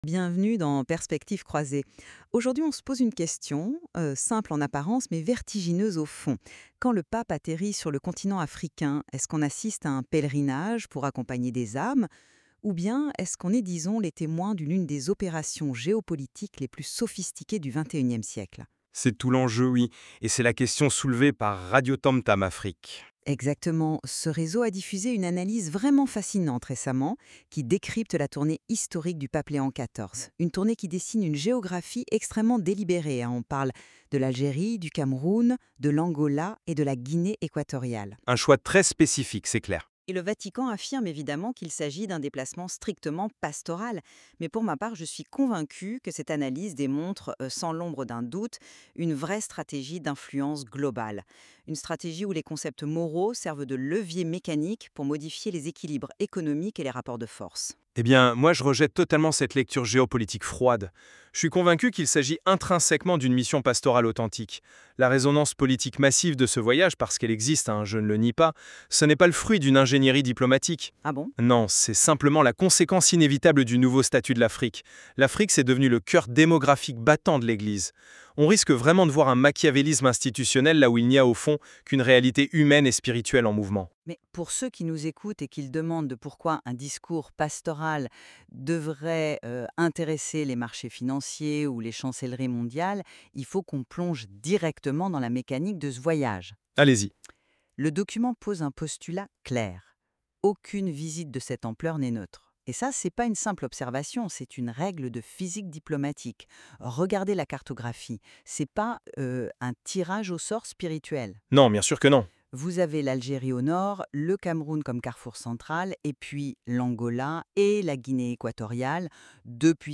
Une émission signée RADIOTAMTAM AFRICA – média indépendant.